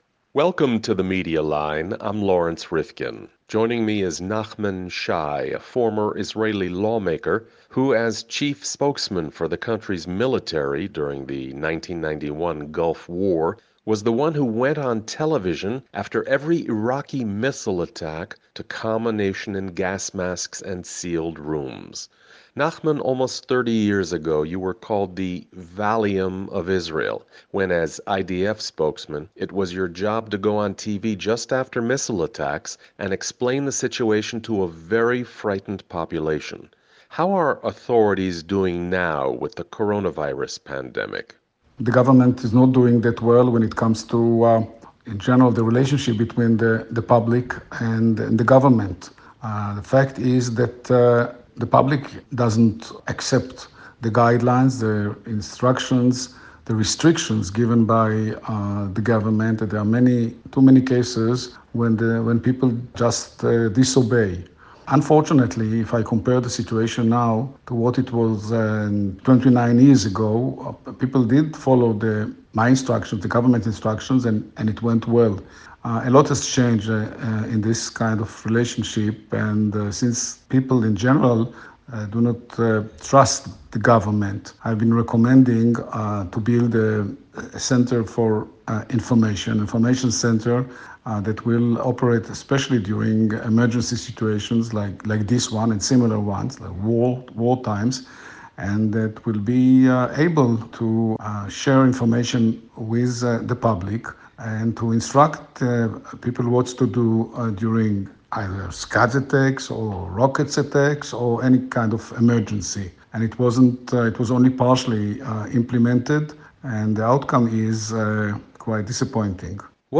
The Media Line speaks with Nachman Shai, once called the ‘Valium of Israel,’ for his take on the way authorities have been mobilizing the public